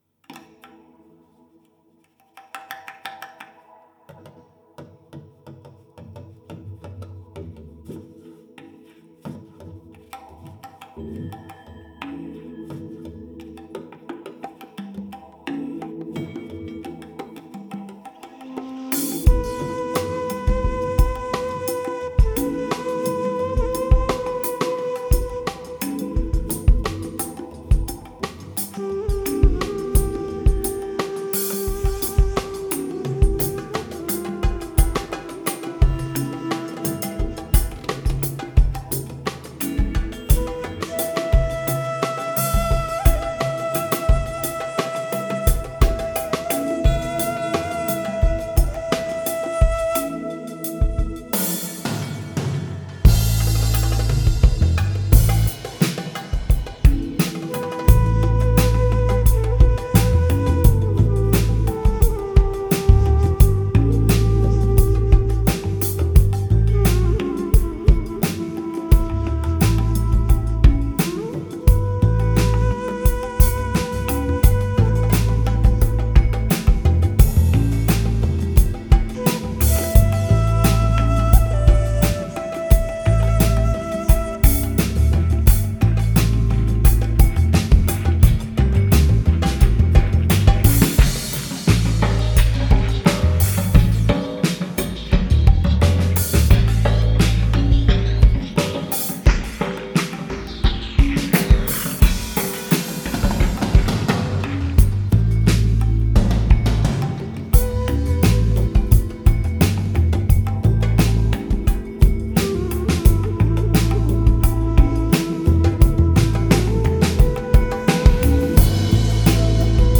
Genre: Downtempo, Chillout, World.